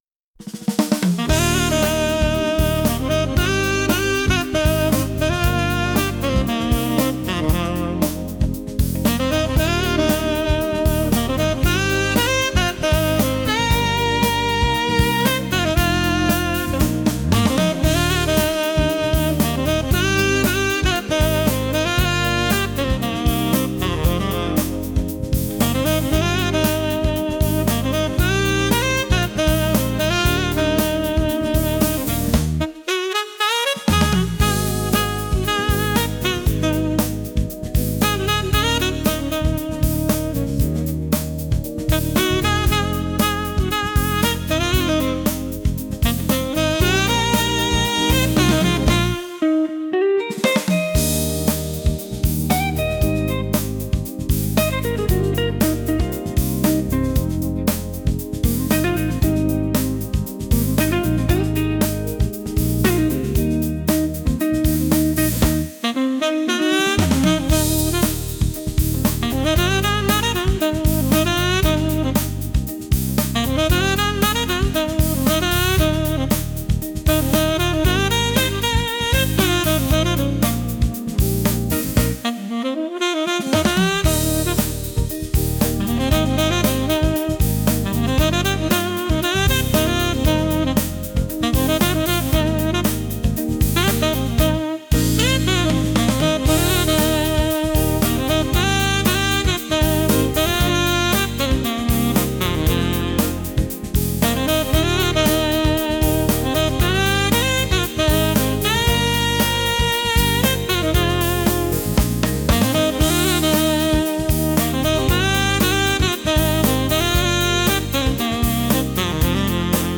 足取りが軽くなるほど気持ちいいときのBGM